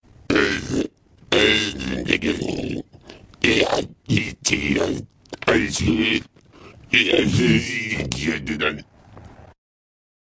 噪声和尖叫声 " 噪声3
描述：采用Sond Forge 10 + VST FX制成
标签： 机械 电子 噪音 外国人
声道立体声